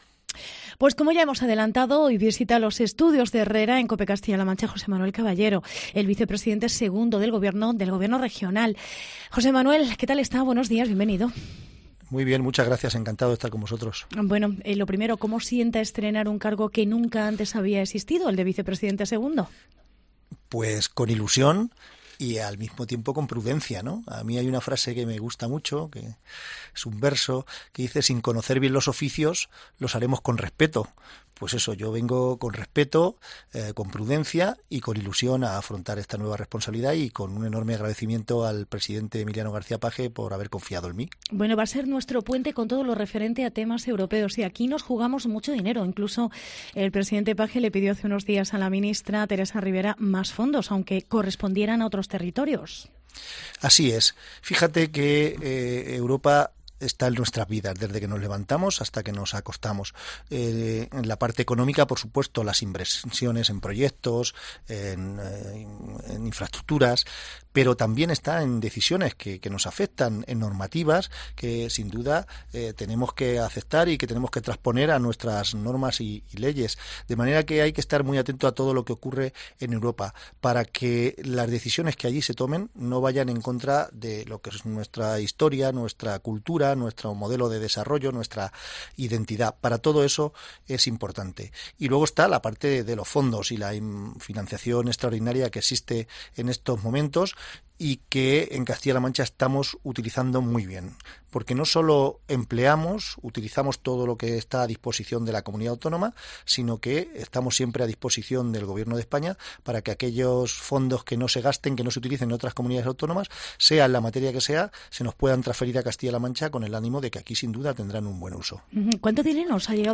Entrevista con José Manuel Caballero, Vpte 2º del gobierno de CLM